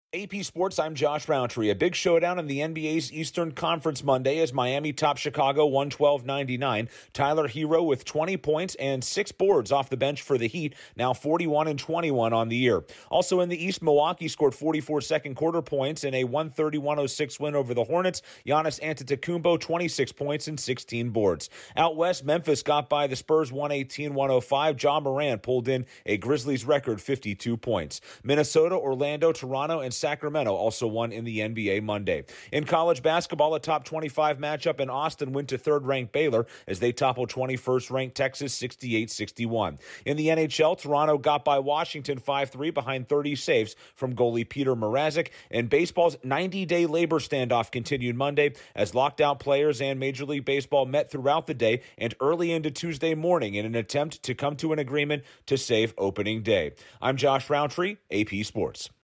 Heat stay hot in the NBA, Milwaukee, Memphis also win; Baylor outshoots Texas; Toronto outskates Washington; Baseball talks continue. Correspondent